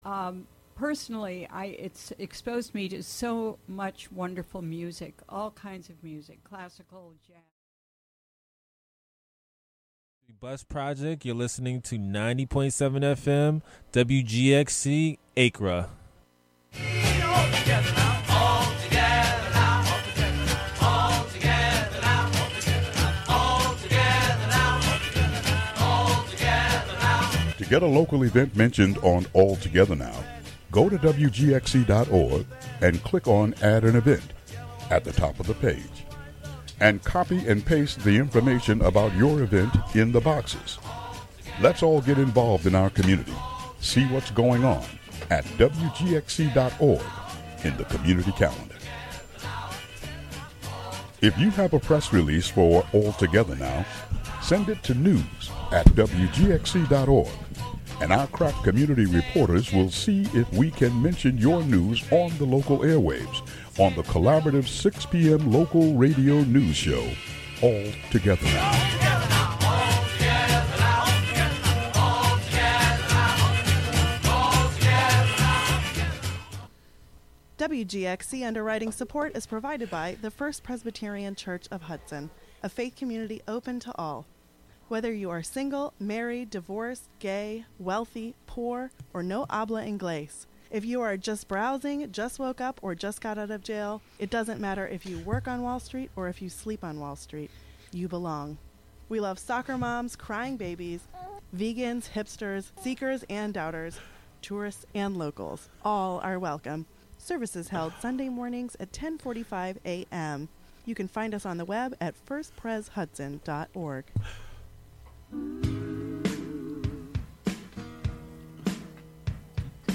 The show features interviews and discussion with political figures and newsmakers on a range of topics of importance to Columbia County, N.Y., and beyond.